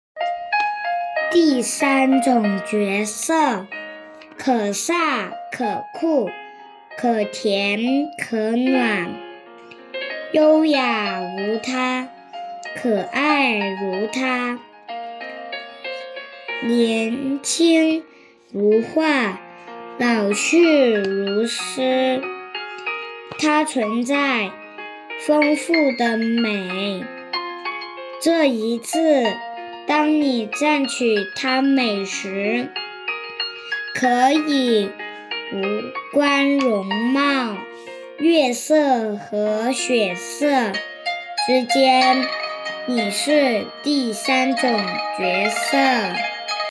第三种绝色 Posted on 2023年5月25日 2023年5月29日 by admin 喜提线上朗读三等奖！